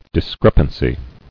[dis·crep·an·cy]